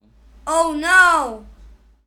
anxious cartoon child female funny girl human little sound effect free sound royalty free Funny